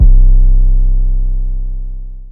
808 - FEEL.wav